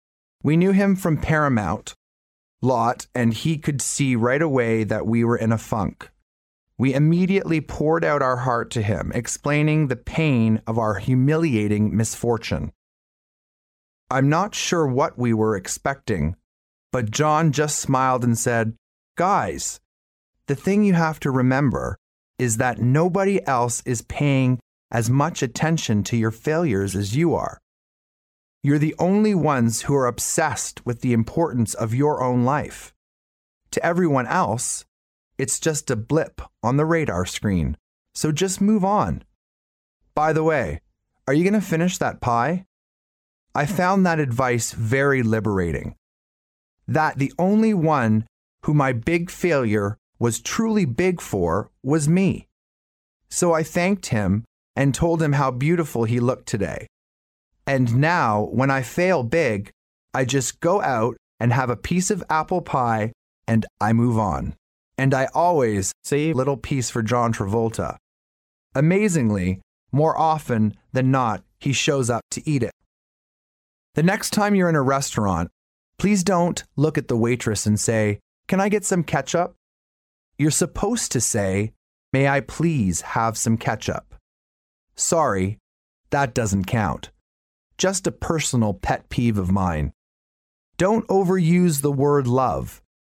名校励志英语演讲 93:如何实现你的梦想 听力文件下载—在线英语听力室
借音频听演讲，感受现场的气氛，聆听名人之声，感悟世界级人物送给大学毕业生的成功忠告。